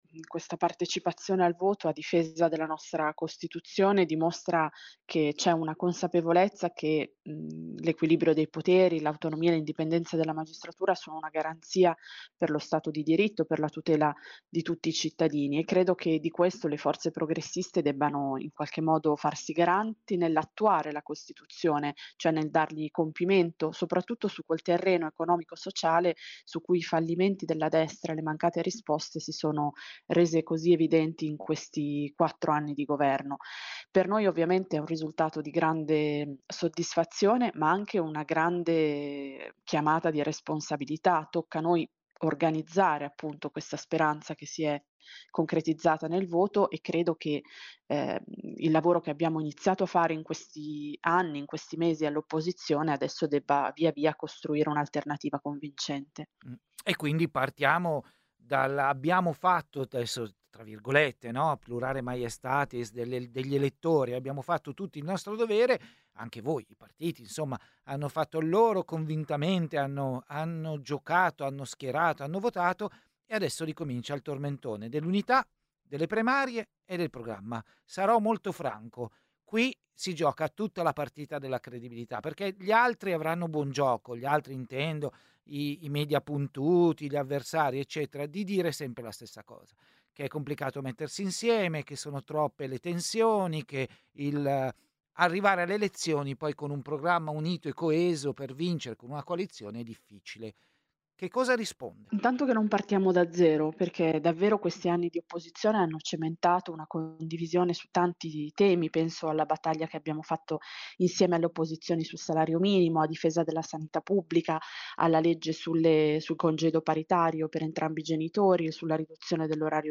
“Siamo consapevoli che dentro quei 14 milioni e passa di voti c’è un pezzo di elettorato che non è detto tornerà a votare e a noi questa consapevolezza è una chiamata di responsabilità”, commenta Chiara Braga, Capogruppo del PD alla Camera che rivendica la vocazione testardamente unitaria del partito.